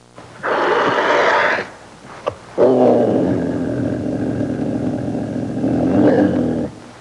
Growling Bear Sound Effect
Download a high-quality growling bear sound effect.
growling-bear-2.mp3